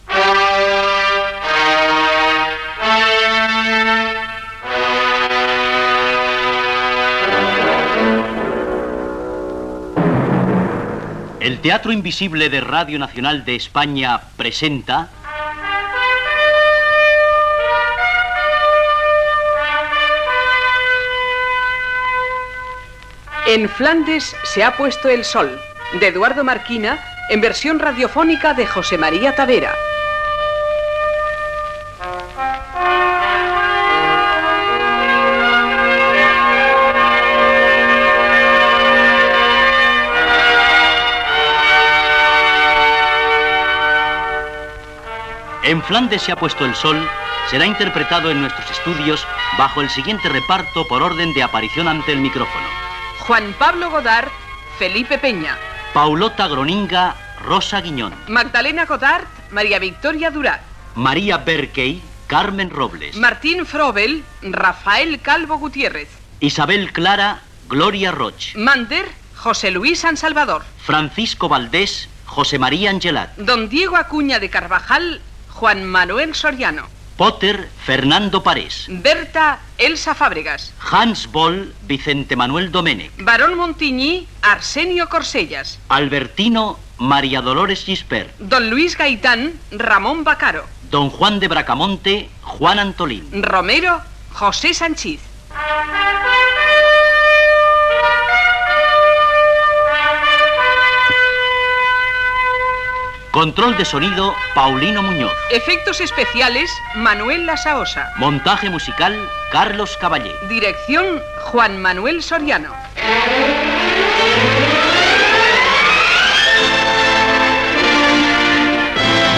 Careta de la versió radiofònica de l'obra "En Flandes se ha puesto el sol" d'Eduardo Marquina amb els crèdits.
Ficció
FM